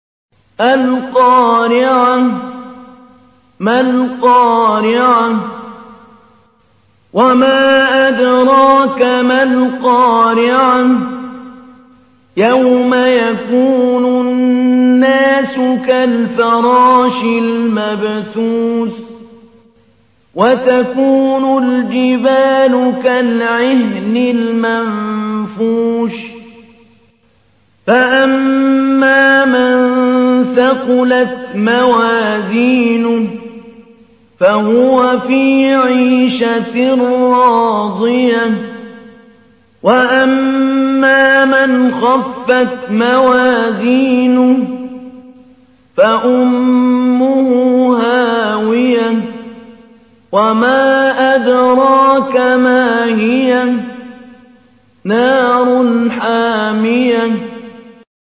تحميل : 101. سورة القارعة / القارئ محمود علي البنا / القرآن الكريم / موقع يا حسين